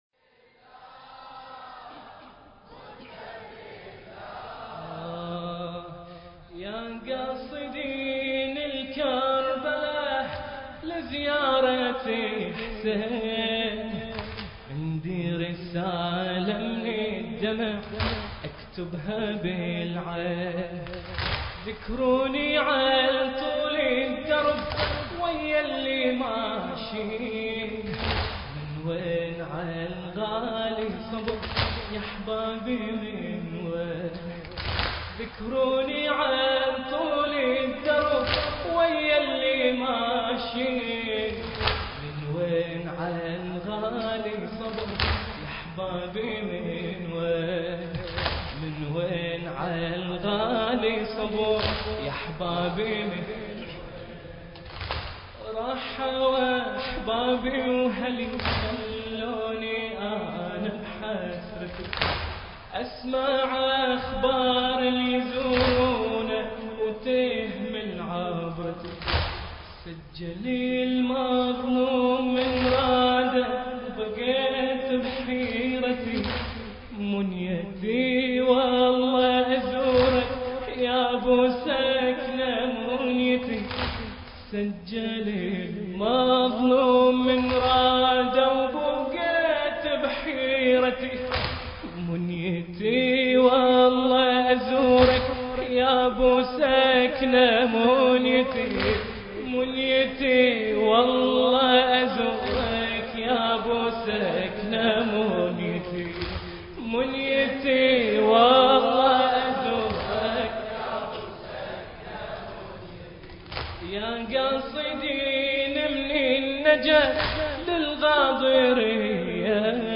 المراثي
الحجم: 1.83 MB الشاعر: عبد الله القرمزي المكان: مسجد الرسول الأعظم (صلى الله عليه وآله وسلم) -سلطنة عمان التاريخ: ليلة 20 صفر 1437 للهجرة